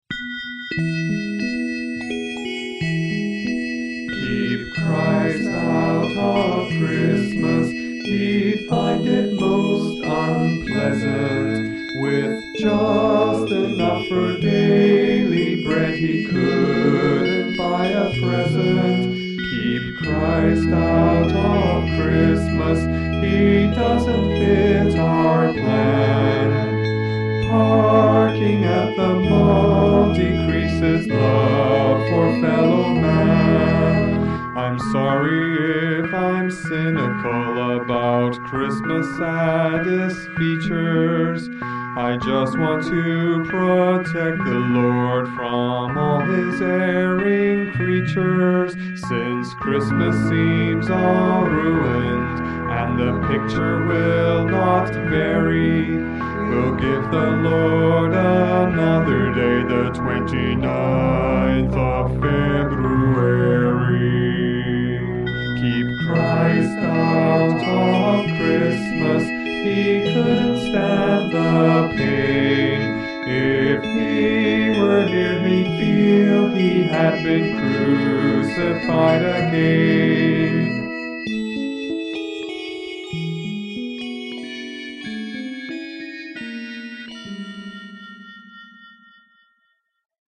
"Keep Christ Out of Christmas", in which I guess I found it hard to sing "couldn't" clearly.